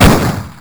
PISTOL.wav